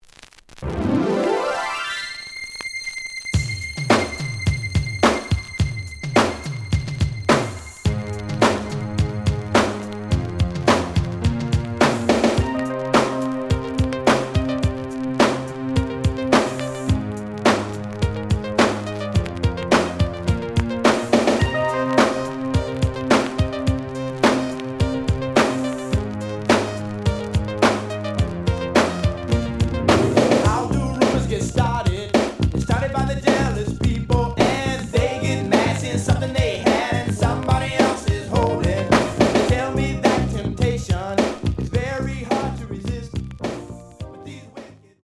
The audio sample is recorded from the actual item.
●Genre: Funk, 80's / 90's Funk
Slight damage on both side labels. Plays good.)